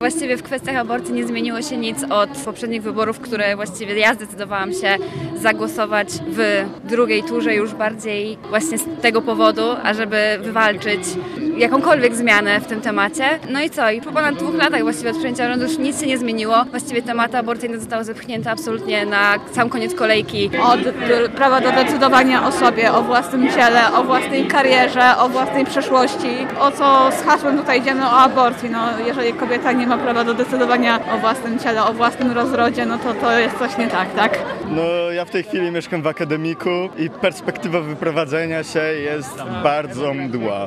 Posłuchaj uczestników Manify: https